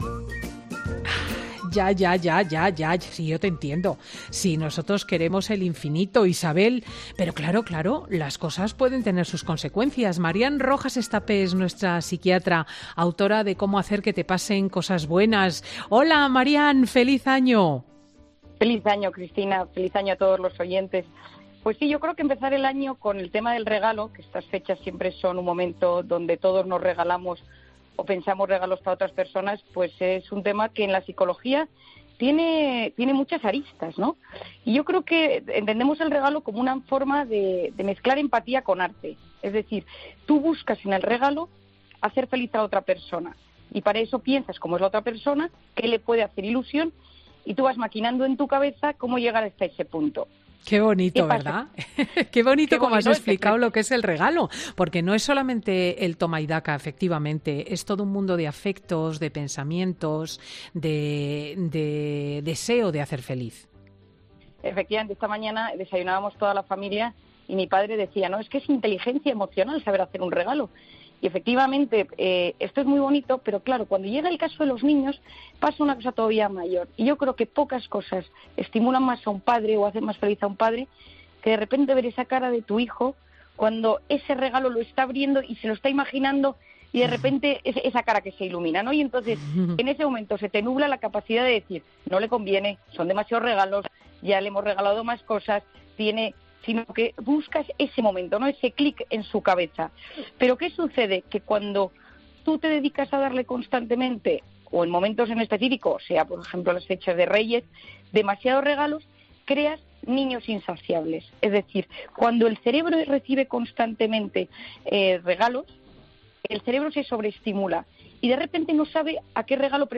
La psiquiatra da las claves para no saturar a nuestros hijos y saber regalar con moderación